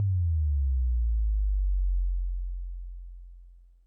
Tm8_Kick13.wav